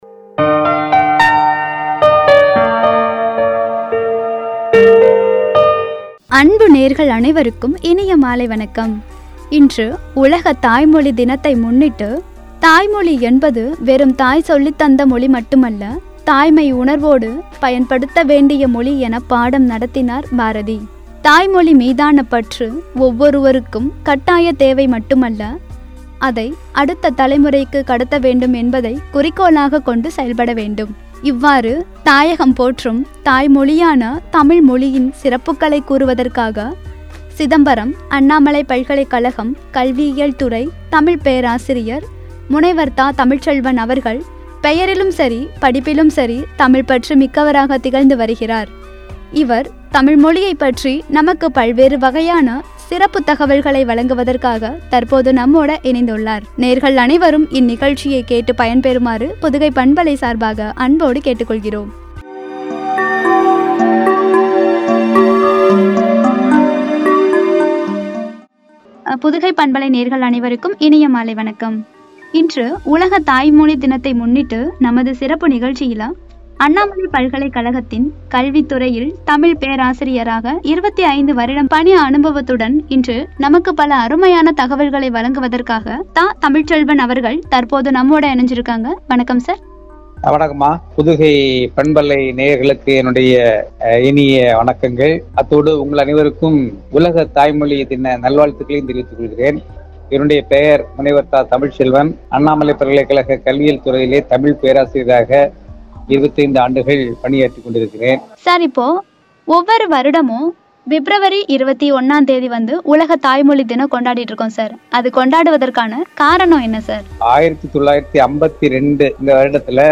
“தாய்மொழியின் தனித்துவம்” என்ற தலைப்பில் வழங்கிய உரையாடல்.